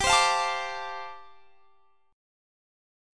G9sus4 Chord
Listen to G9sus4 strummed